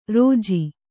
♪ rōji